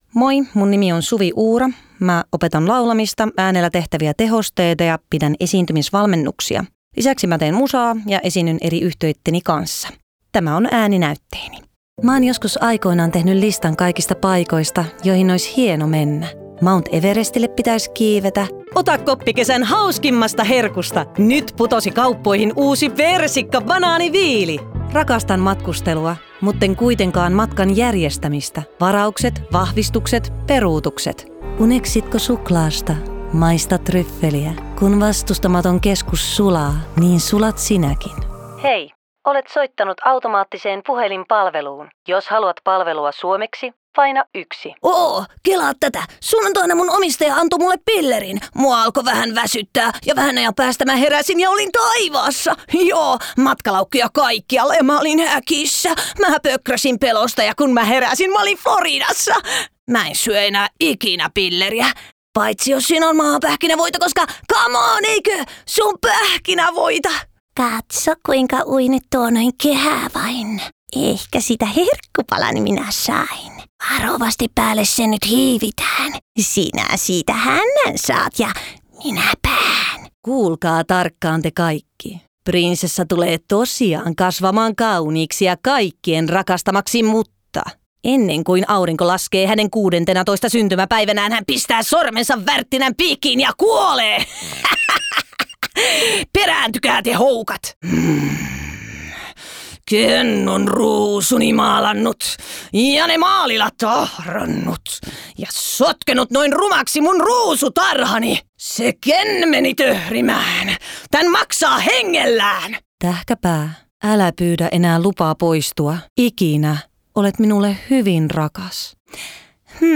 Ääninäyttelijän työt
Etsitkö henkilöä, joka osaa tuottaa monipuolisesti eri ääniä?